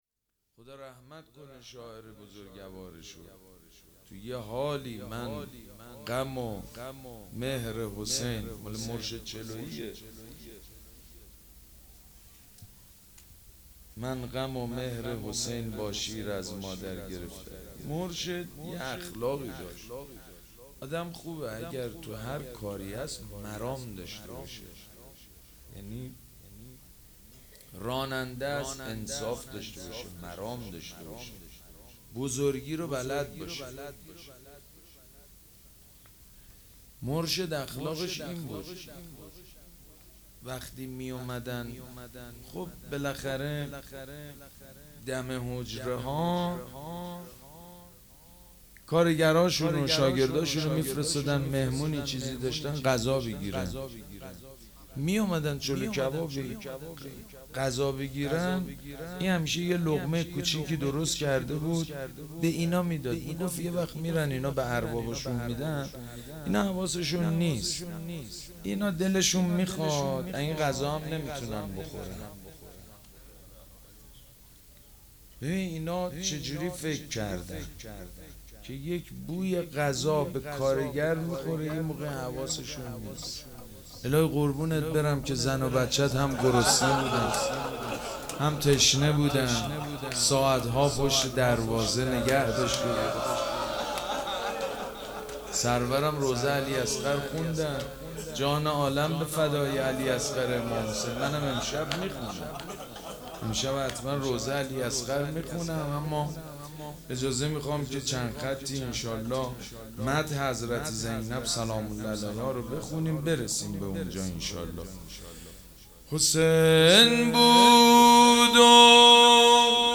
مراسم عزاداری شام شهادت حضرت رقیه سلام الله علیها
مدح
مراسم عزاداری شهادت امام حسن (ع) و حضرت رقیه (س)